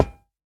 latest / assets / minecraft / sounds / block / iron / break7.ogg
break7.ogg